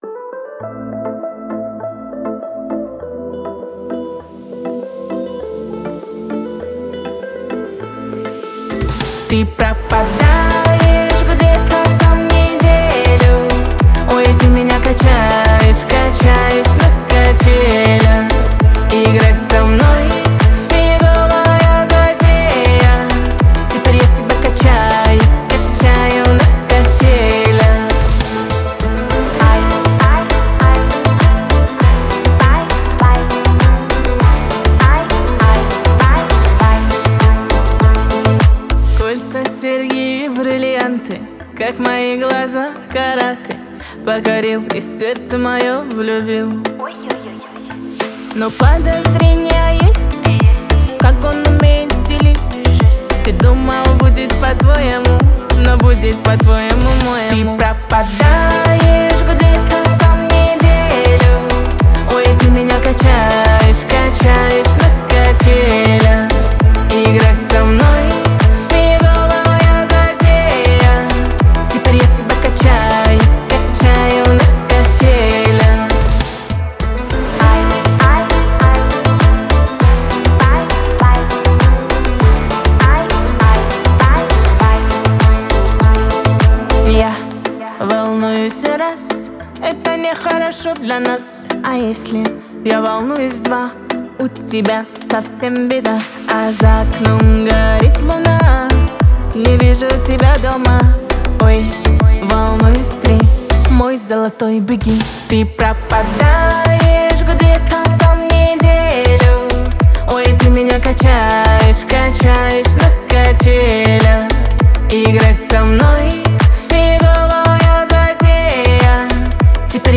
Категория Рэп